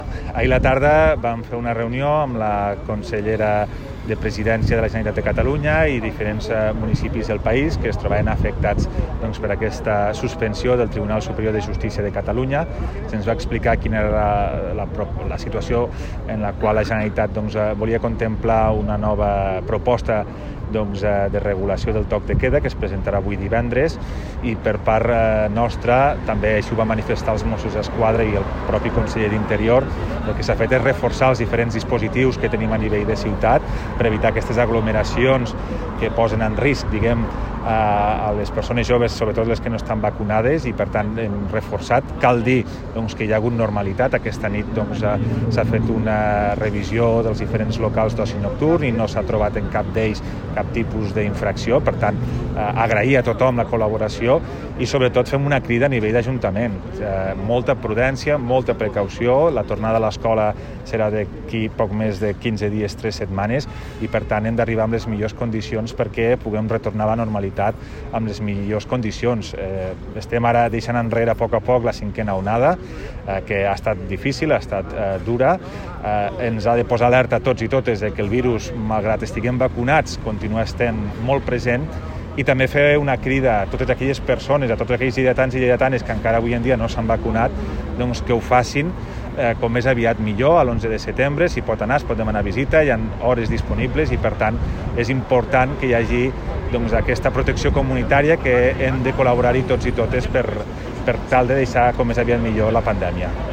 tall-de-veu-de-toni-postius-sobre-el-reforc-del-dispositiu-de-seguretat